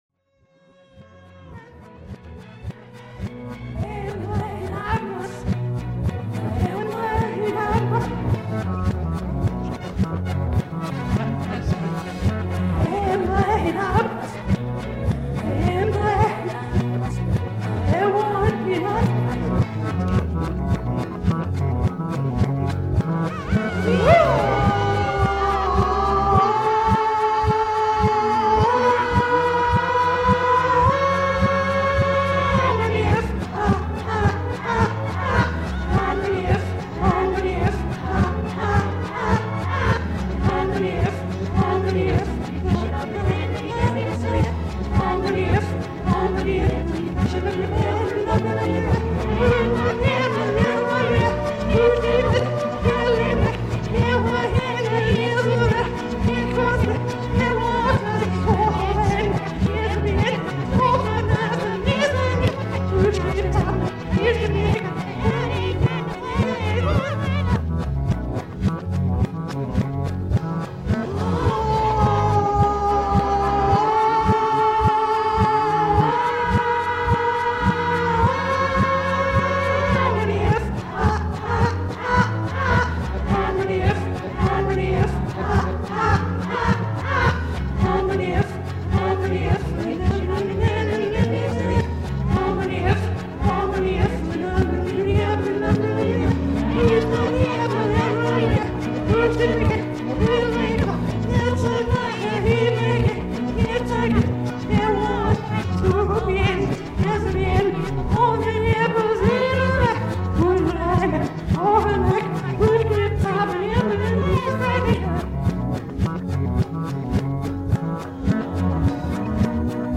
lead vocals
bass & rythmic vocals